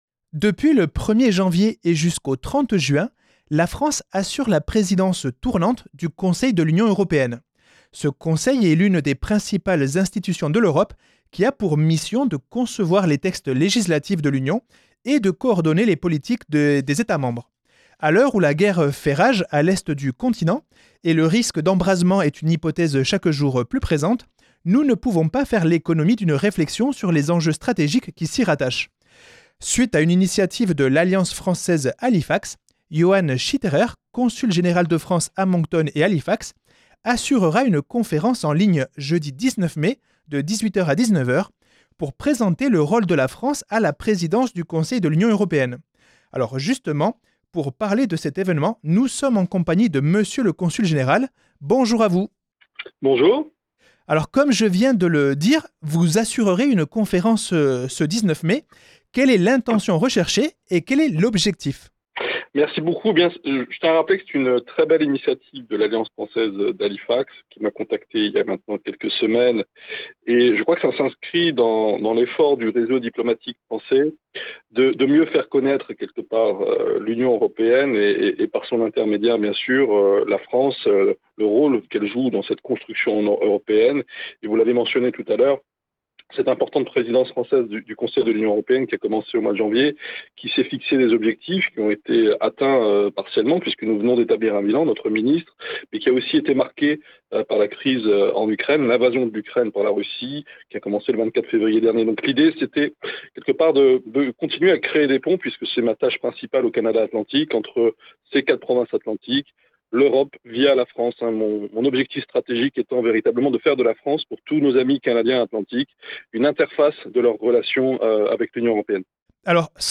Le Consul général de France à Moncton explique les enjeux de la Présidence française de l’UE